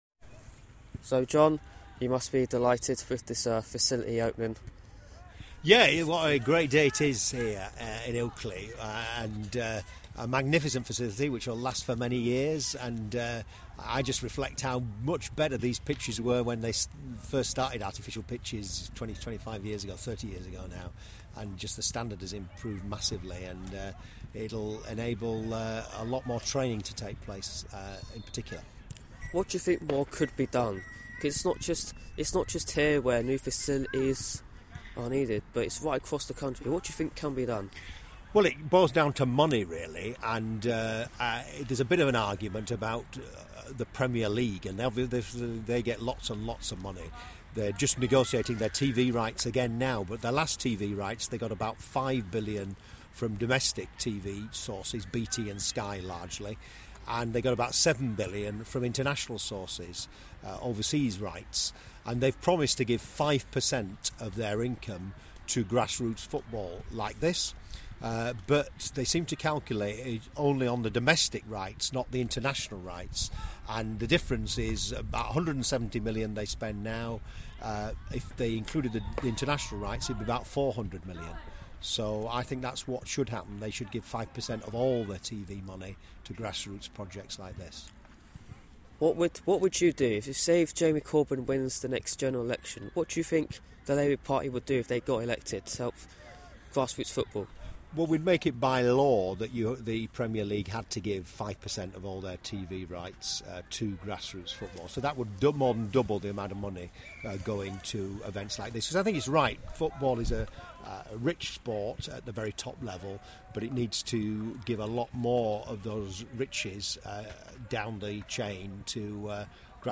Labour MP for Keighley and Ilkley John Grogan speaking at the Ilkley Town 3G pitch official launch.